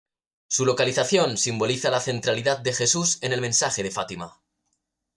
men‧sa‧je
/menˈsaxe/